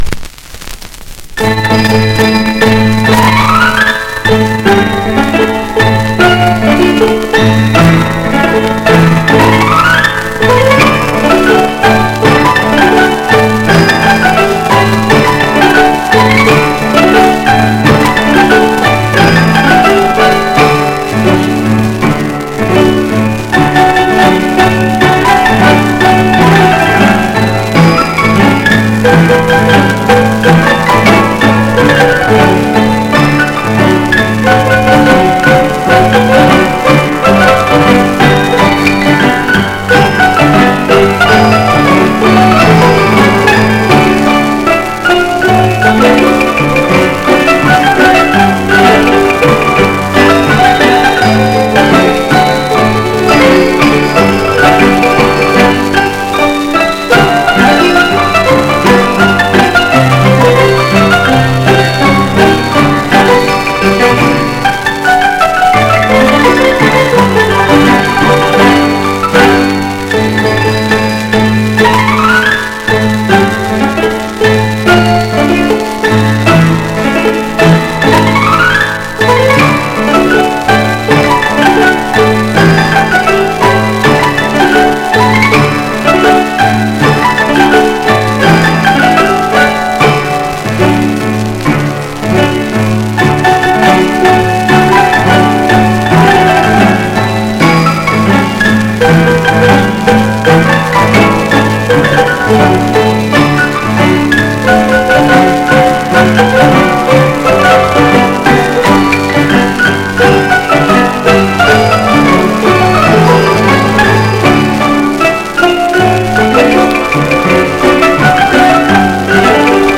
1 disco : 78 rpm ; 25 cm.